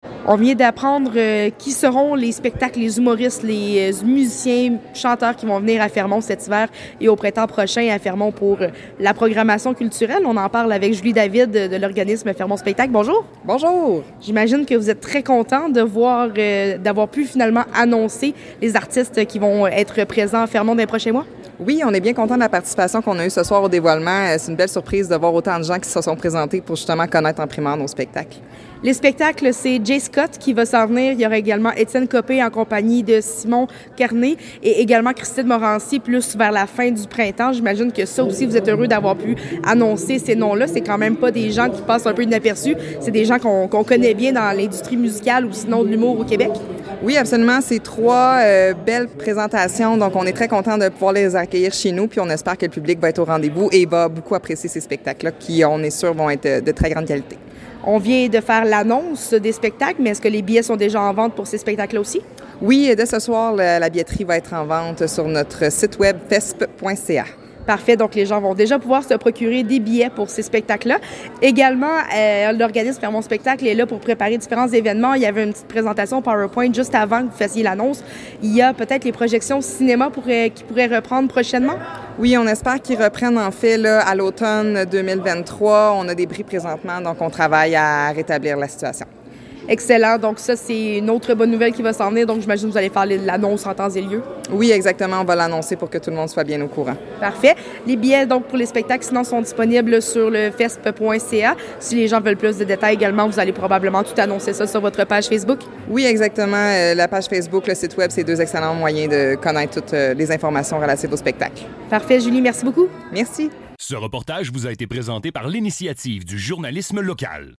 En entrevue lors du lancement